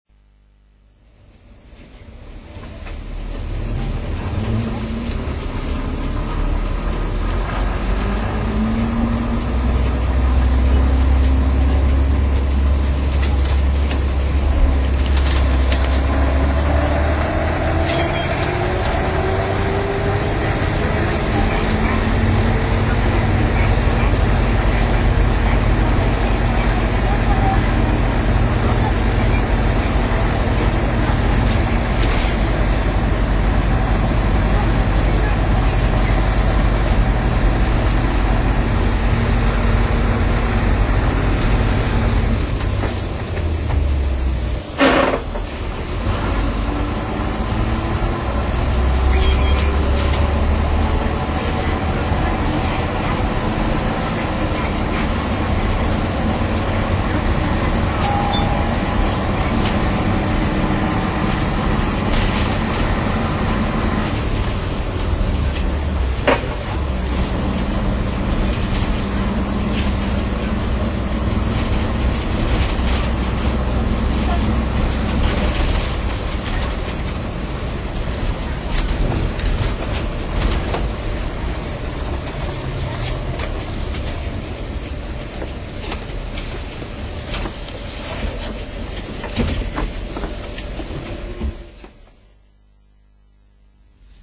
富士５Ｅボディを架装したＣＪＭ最後の走行音です。
上で紹介している３Ｅボディ車より低音が強くなっているのが特徴です。
ＰＡＲＴ１　綾１３系統（当時は系統番号なし） 国分〜国分寺台第２ （３８４ＫＢ）